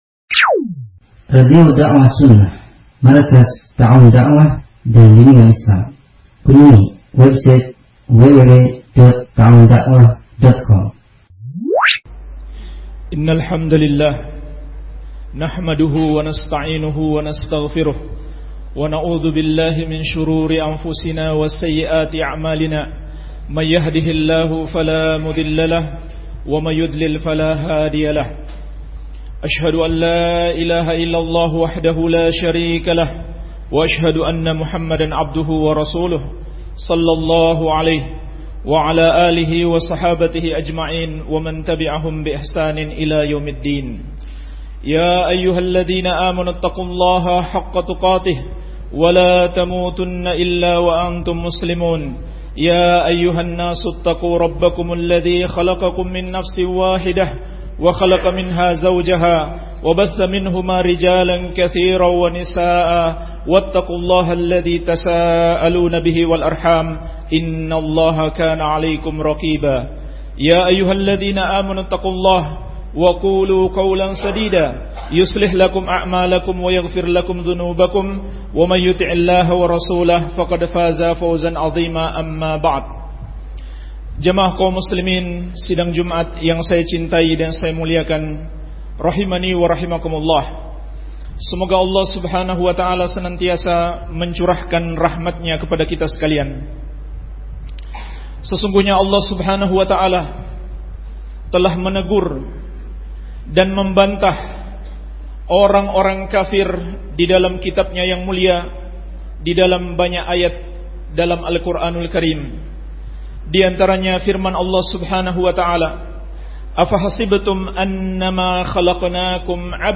AUDIO KAJIAN